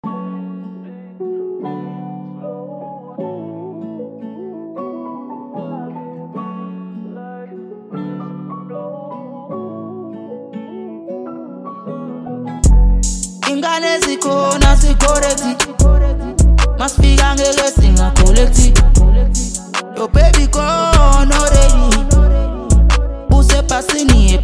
Home » DJ Mix » Hip Hop
South African singer-songsmith